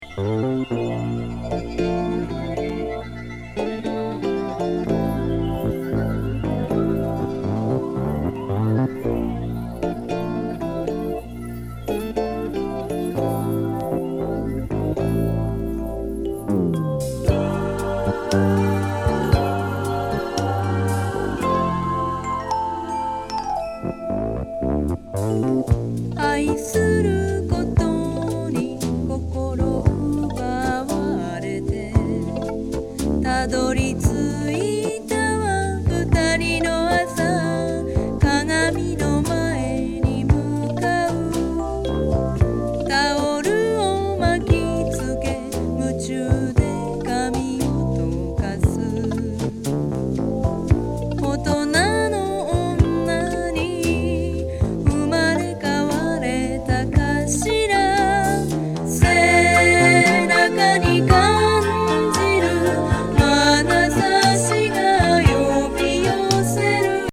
和レアリック・ドリーミー・メロウ・バラード「